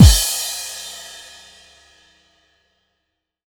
normal-hitfinish.mp3